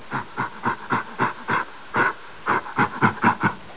c_goril_bat3.wav